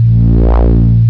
first test for an Alt Skew mode. this is awesome.
Kind of like the original sound I was searching for but even more pronounced “Wow”-like filter sweep!